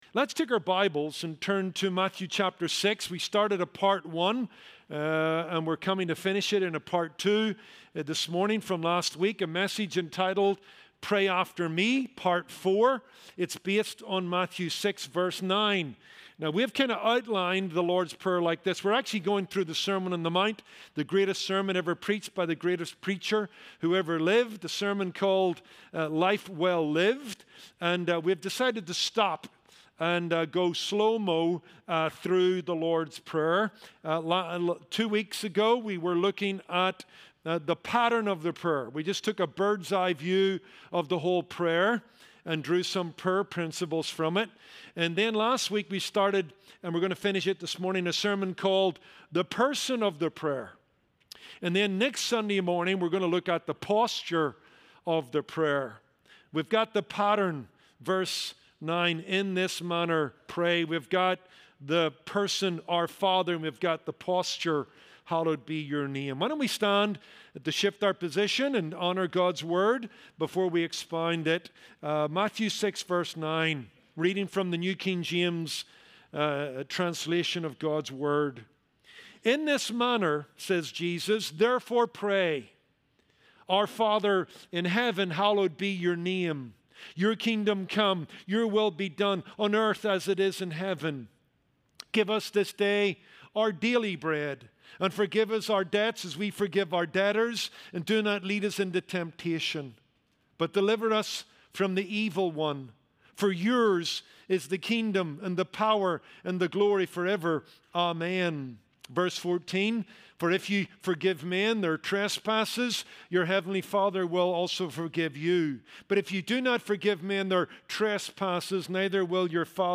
Sermons from the Pulpit of Kindred Community Church